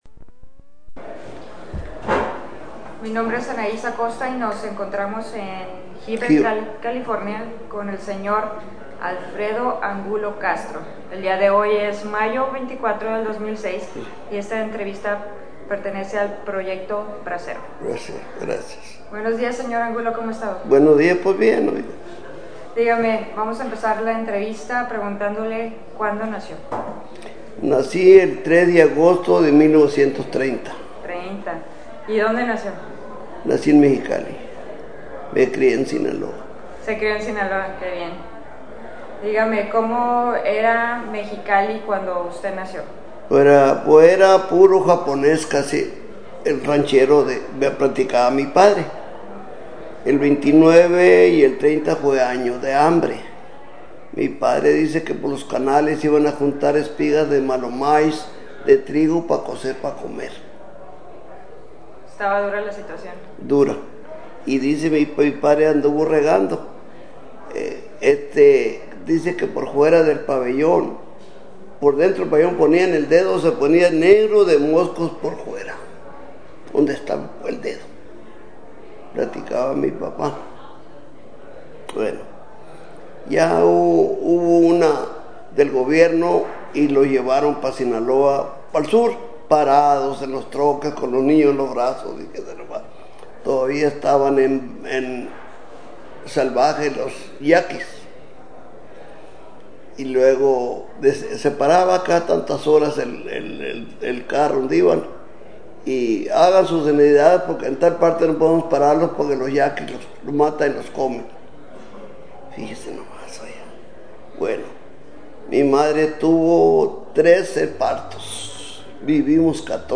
Original Format Mini disc
Location Heber, CA